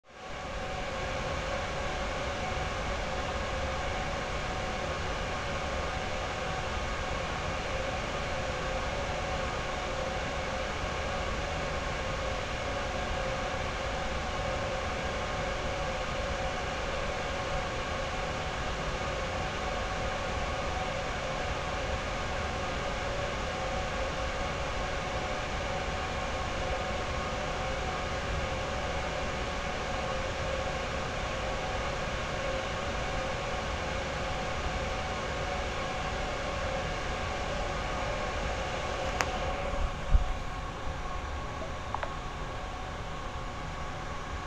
whirry white noise with bonk at the end
computer drone fans lab noise test whirring white sound effect free sound royalty free Memes